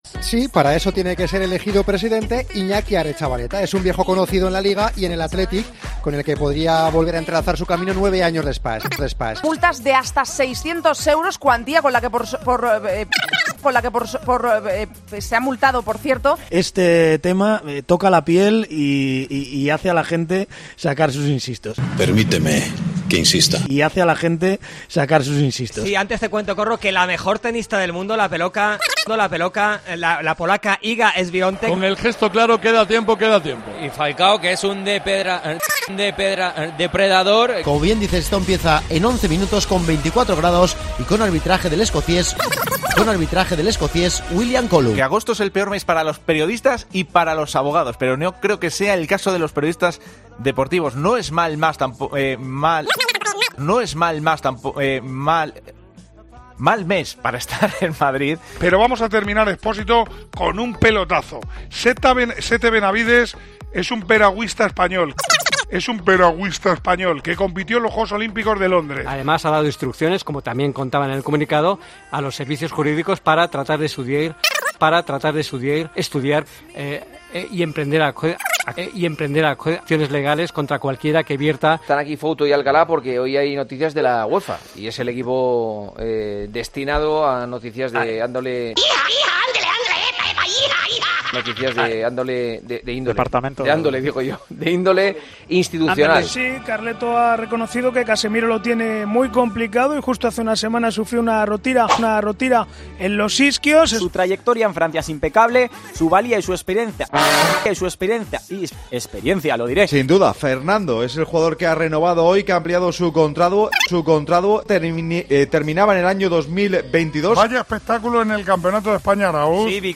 AUDIO: Las mejores trabadas de la redacción de Deportes COPE: Esas palabras que se nos atascan, esos fallos por ir demasiado rápido o simplemente...